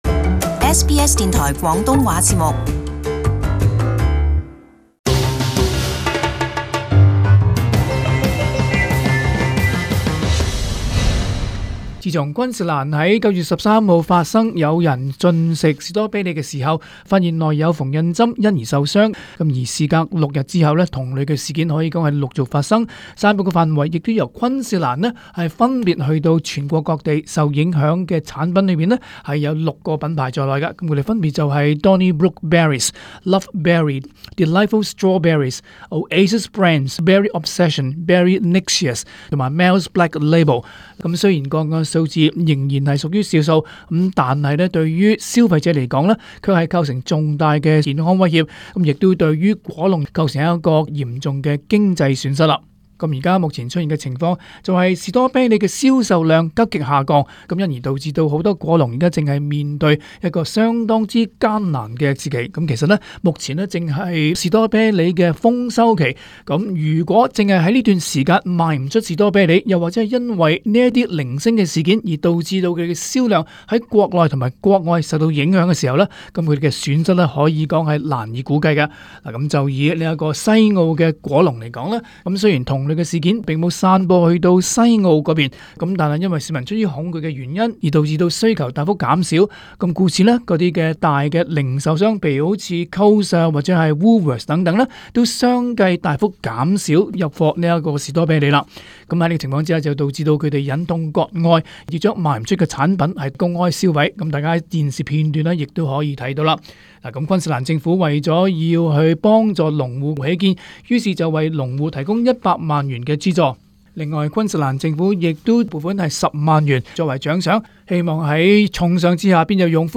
【時事報導】聯邦政府高度關注草莓發現縫紉針事件